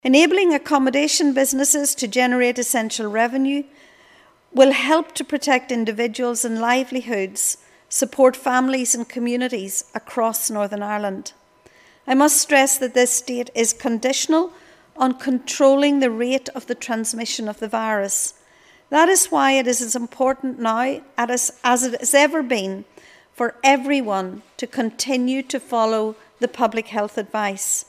July 20th has been confirmed as the target date for re-opening tourist accommodation, but Minister Dodds stressed that will be dependent on public health advice: